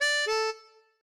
melodica_d1a.ogg